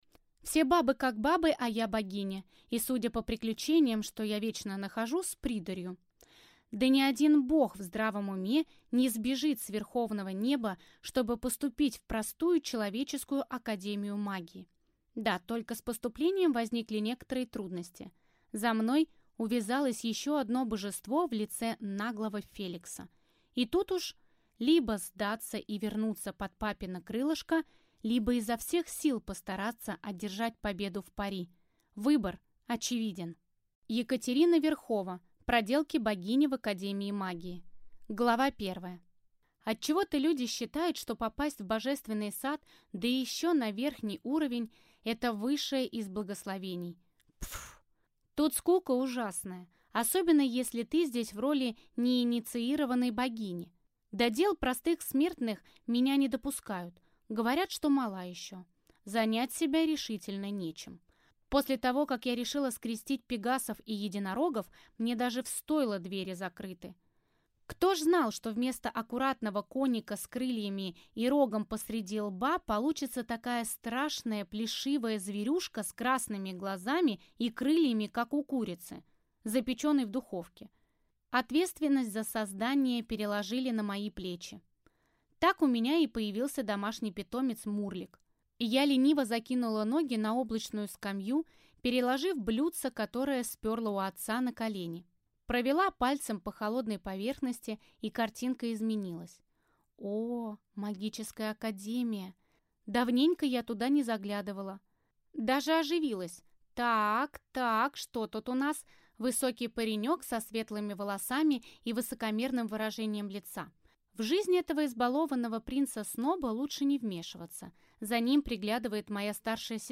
Аудиокнига Проделки богини в академии магии | Библиотека аудиокниг